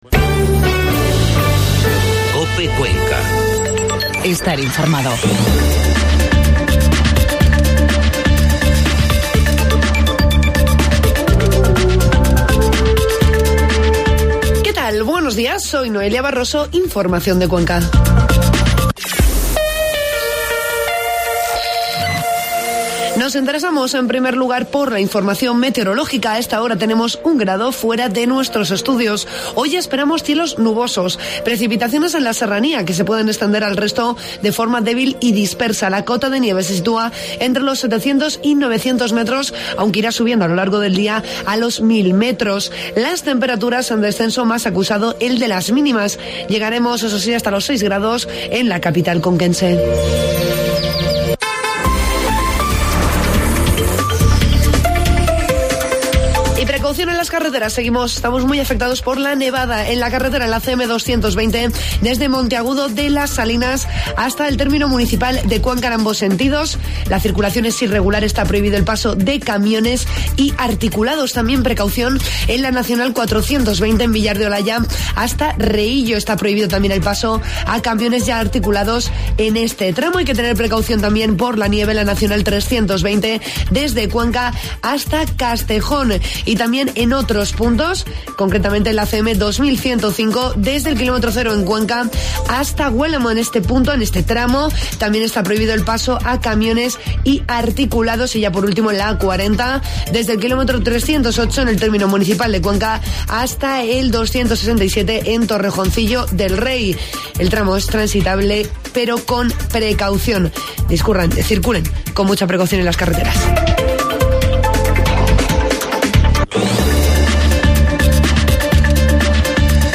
AUDIO: Informativo matinal COPE Cuenca